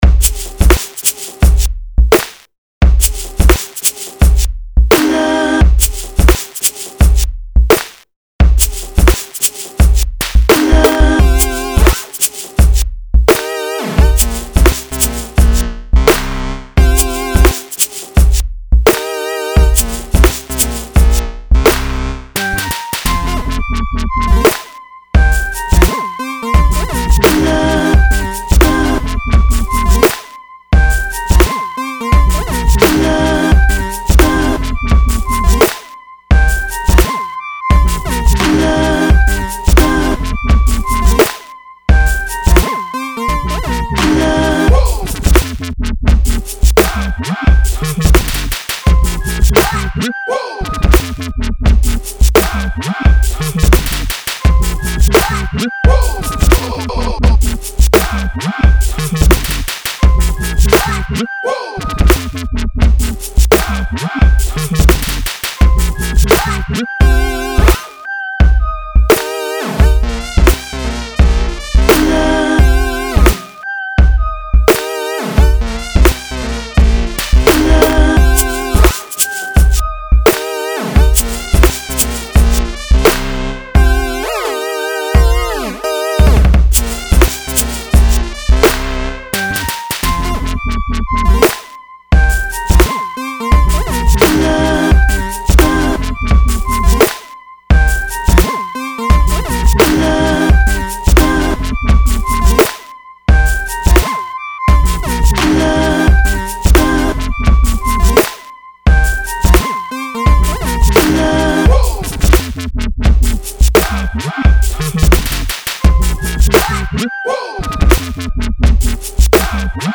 somehow manages to further up the melancholy angst even more